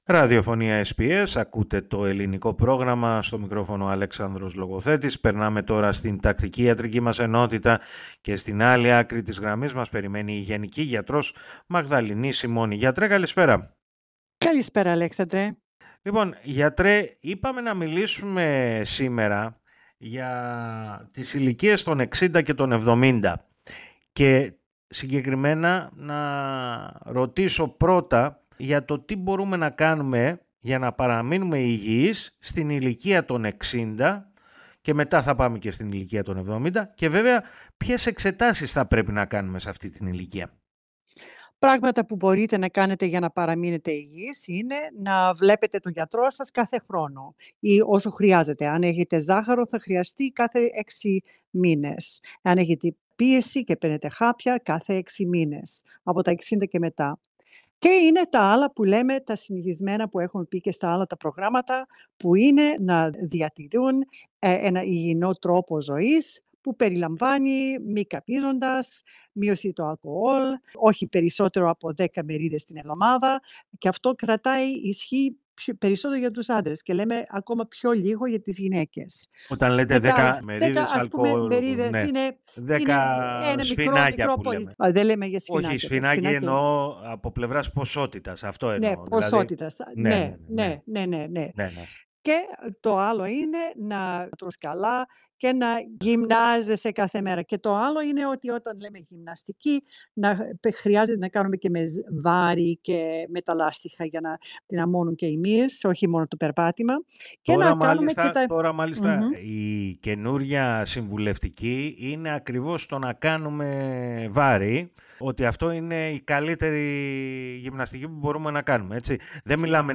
Για συμβουλές και απαντήσεις, ακούστε ολόκληρη τη συνέντευξη, πατώντας PLAY, στην αρχή της σελίδας.